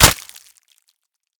Flesh Chop 5 Sound
horror